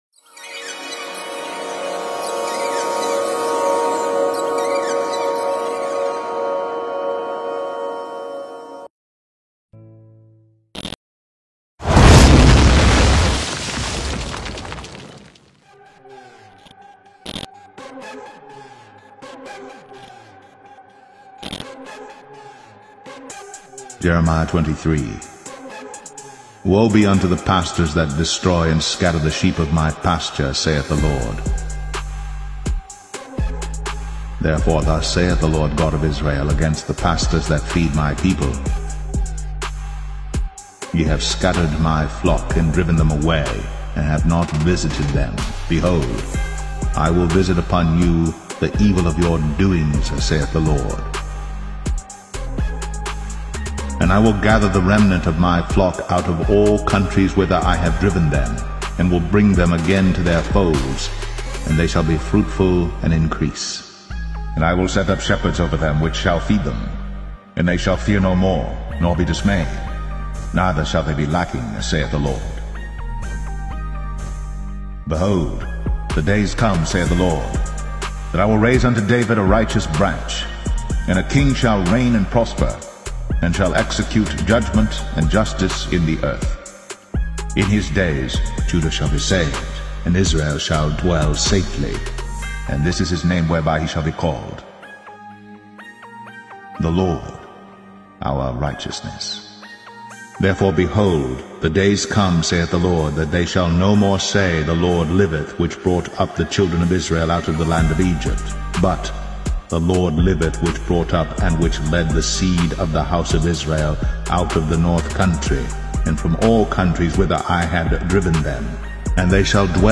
[Mar 23, 2023] CuttingEdge: Jeremiah 23 Bible Reading - RU-READY?